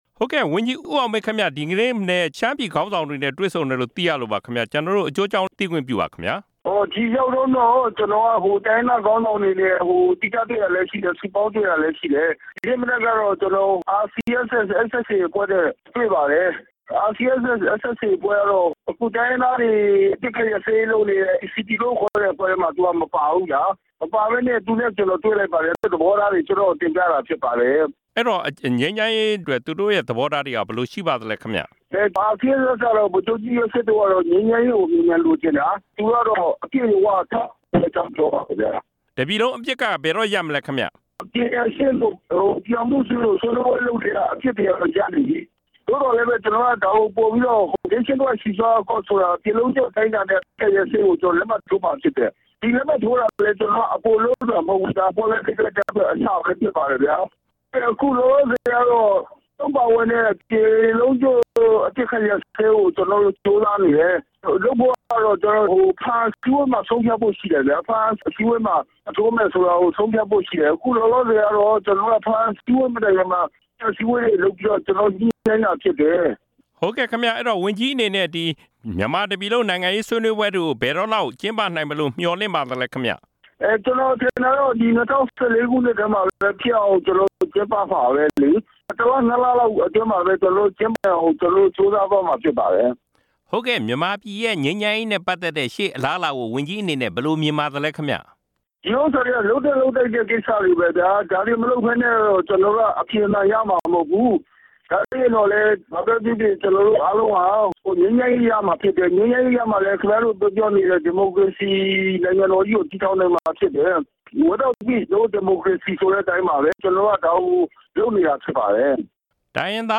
တစ်ပြည်လုံး အပစ်အခတ်ရပ်စဲရေးကို လက်မှတ်ထိုးပြီးနောက် ငြိမ်းချမ်းရေးဆွေးနွေးပွဲတွေကို အမြန်ဆုံးစတင်ဖို့ ကြိုးစားမှာဖြစ်ပြီး အဲဒီလိုငြိမ်းချမ်းရေးရမှ အများပြည်သူတွေမျှော်မှန်းနေတဲ့ ဒီမိုကရေစီနိုင်ငံကို ထူထောင်နိုင်မှာဖြစ်တယ်လို့ မြန်မာငြိမ်းချမ်းရေးအဖွဲ့ခေါင်းဆောင် ဝန်ကြီးဦးအောင်မင်းက RFA ကို ပြောကြားလိုက်ပါတယ်။